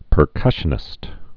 (pər-kŭshə-nĭst)